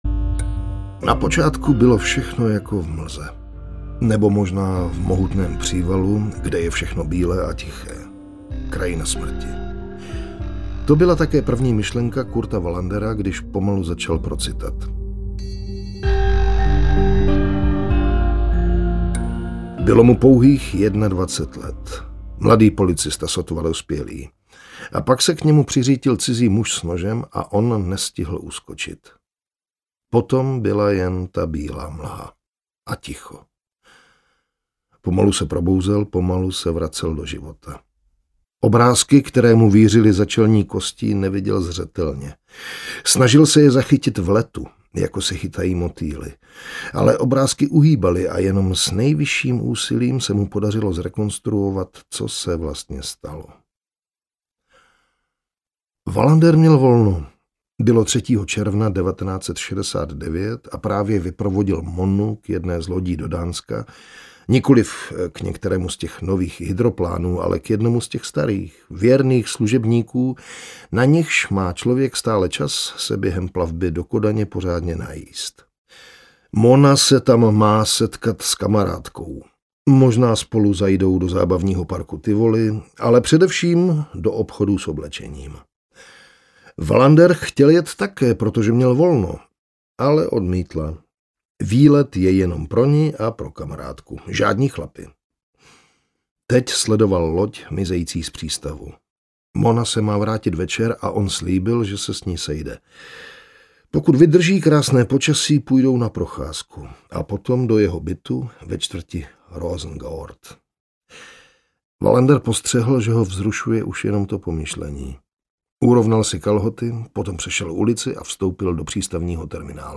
Audiokniha Pyramida – devátý díl ze série případů komisaře Wallandera.